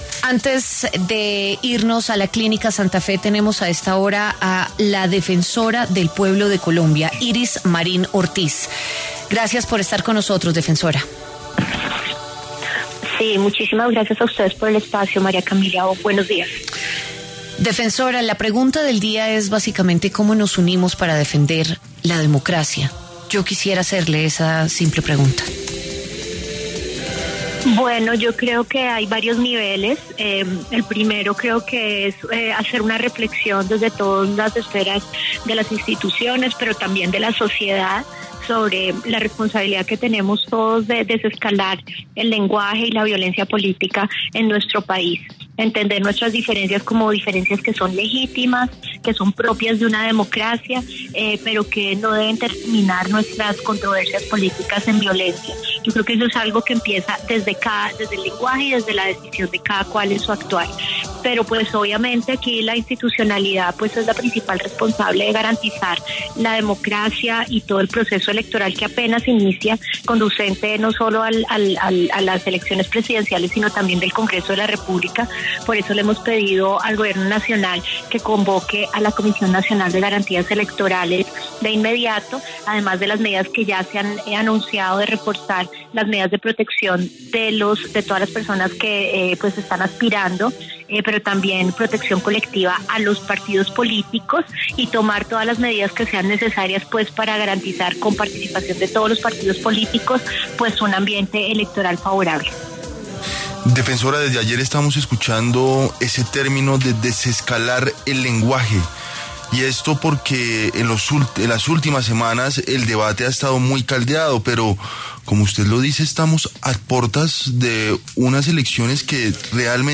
En diálogo con W Fin De Semana, la defensora del Pueblo aseguró que también se le está brindando protección a la familia del menor.